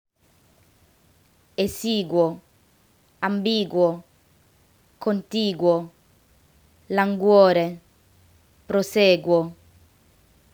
In Italian, G always gets a hard sound when followed by U. However, don’t forget to say the “U” as well!
In other words, “G” and “U” are pronounced as separate letters